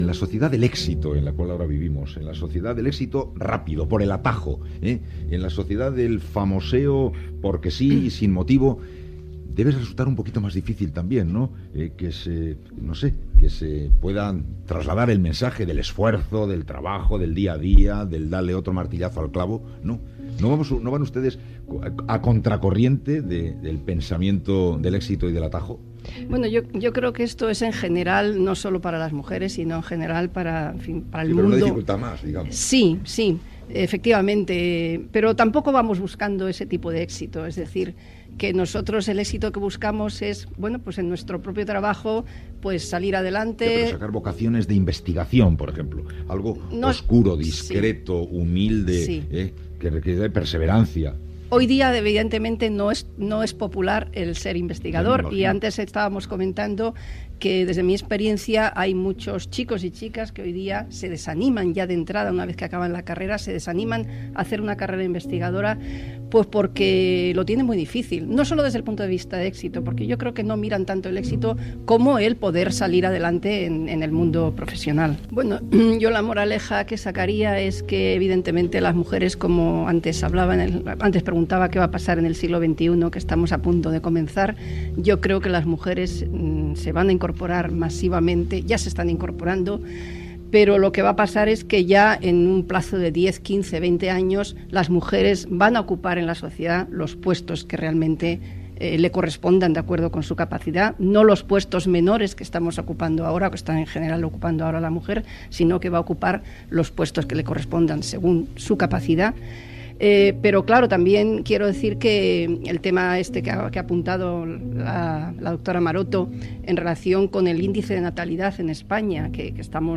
Entrevista a la científica Margarita Salas, especialista en bioquímica.
Info-entreteniment